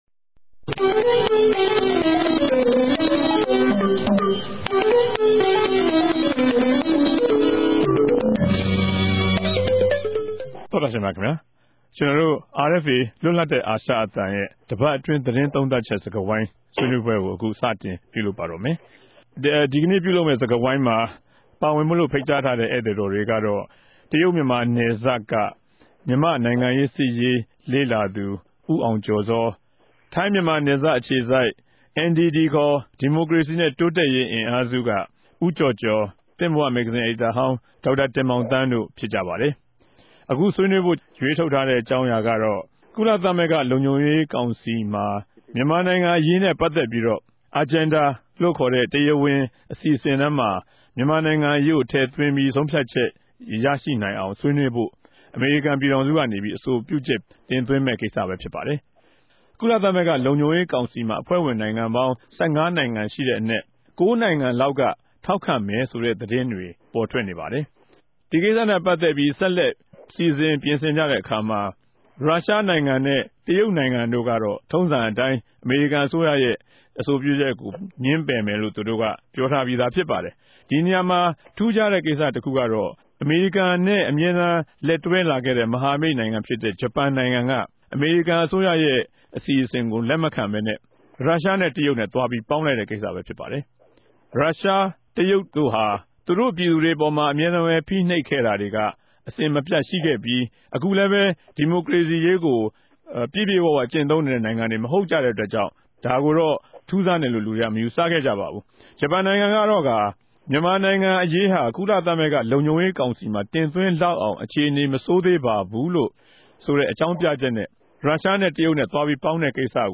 တပတ်အတြင်းသတင်းသုံးသပ်ခဵက် စကားဝိုင်း (၂၀၀၆ ဇြန်လ ၃ရက်)